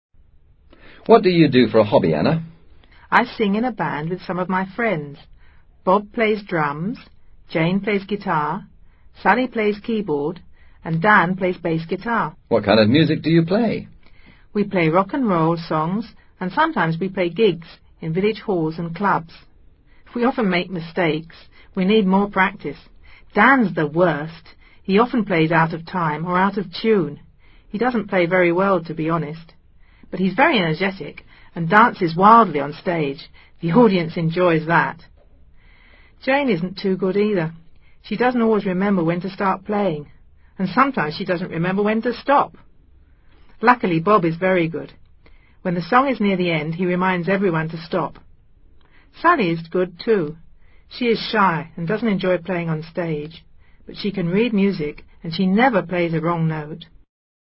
Una joven habla sobre su grupo de música.